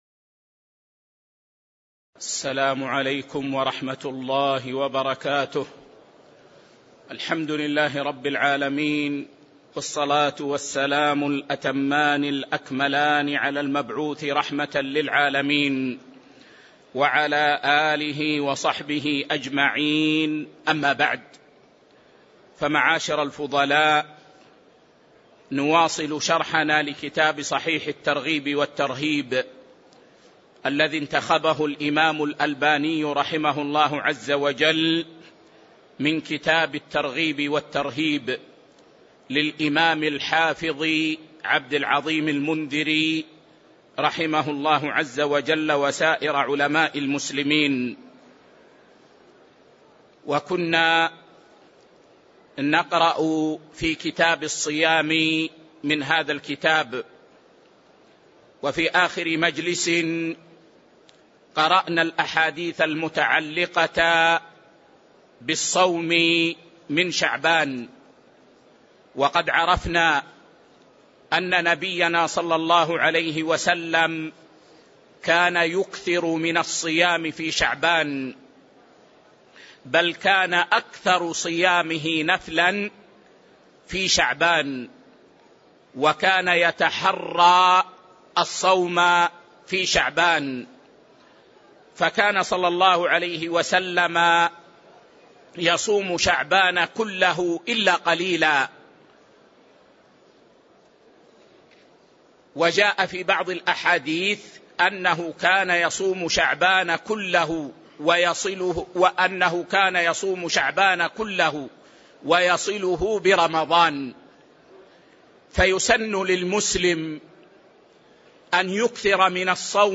تاريخ النشر ٤ شعبان ١٤٤٥ هـ المكان: المسجد النبوي الشيخ